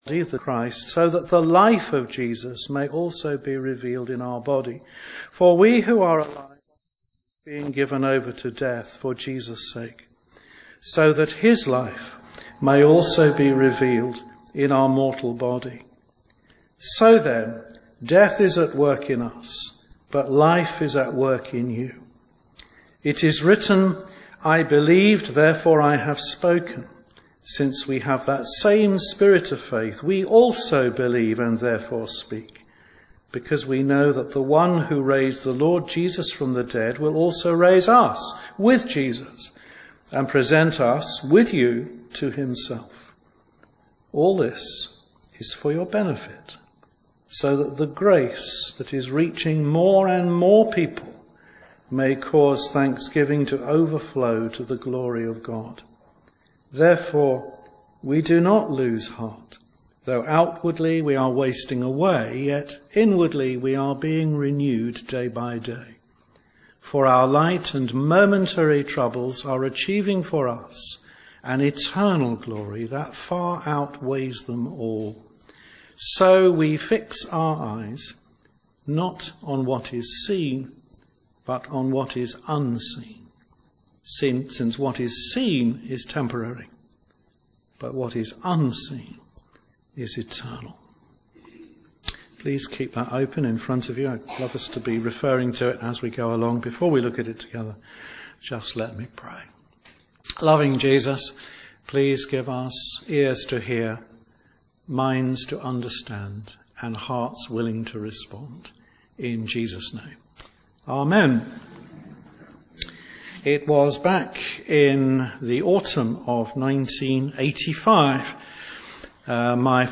Media for Senior Fellowship on Tue 09th Jan 2024 10:30 Speaker